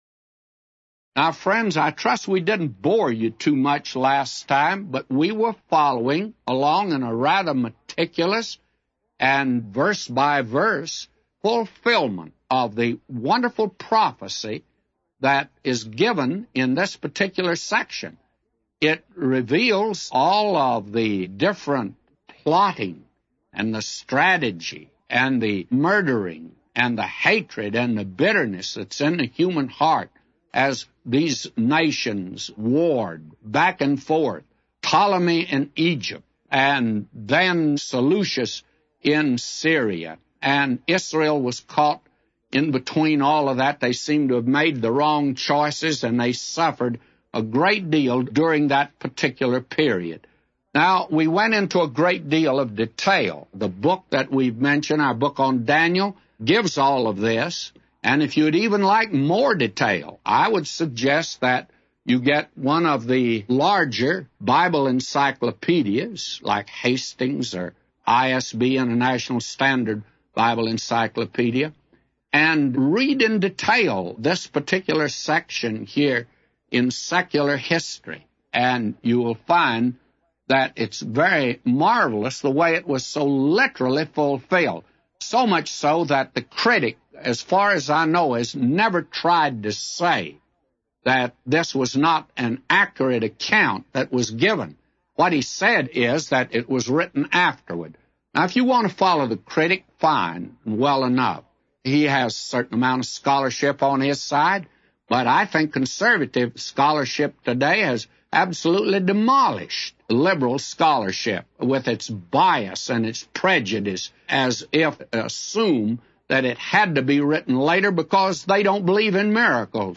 A Commentary By J Vernon MCgee For Daniel 11:15-999